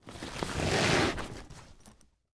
Duffle Bag Lift Ground Sound
household